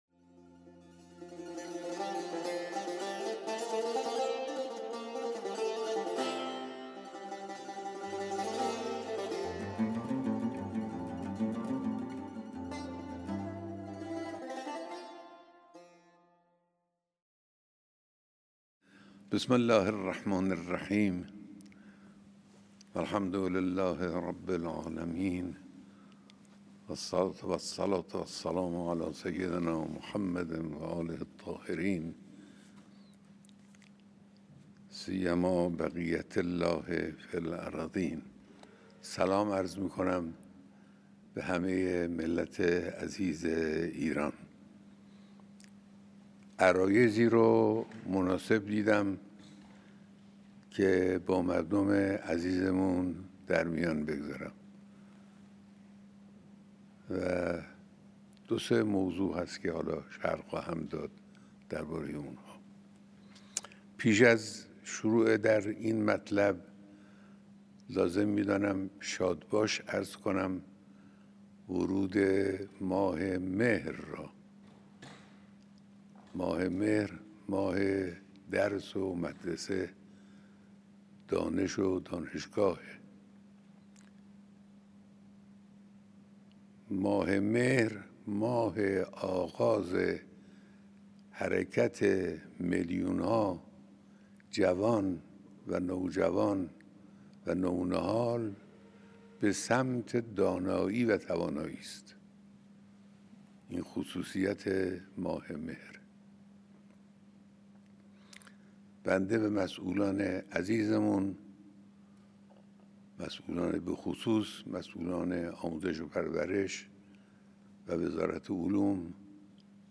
بیانات خطاب به ملت ایران